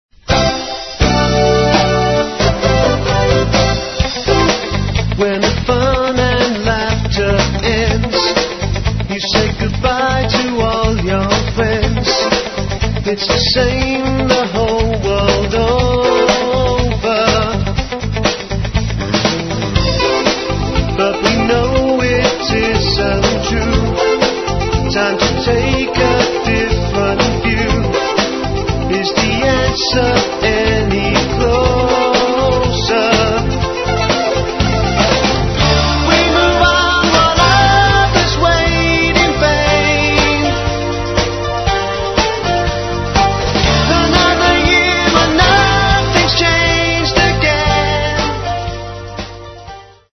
Diploma Studio, Malden, Essex 1990.